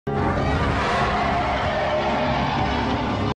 It's Chameleon's roar.